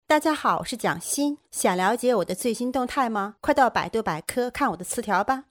女国145_动画_模仿_模仿蒋欣音频.mp3